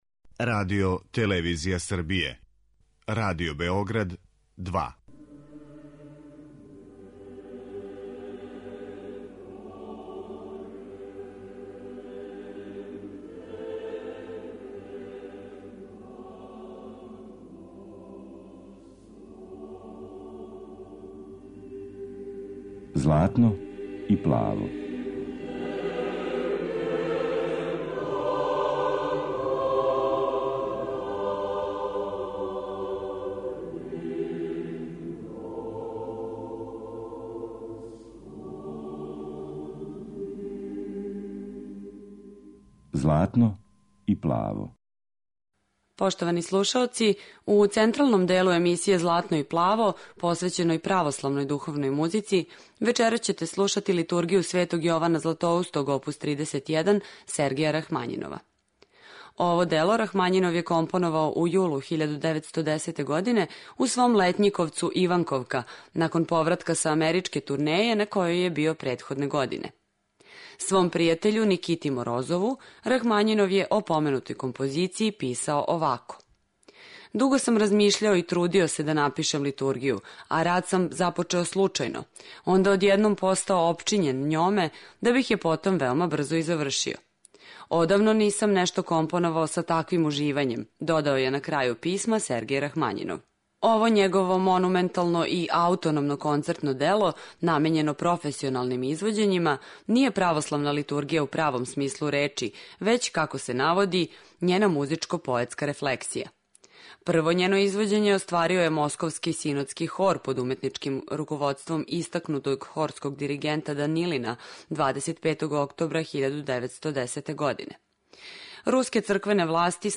Емисија о православној духовној музици